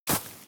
GravelStep2.wav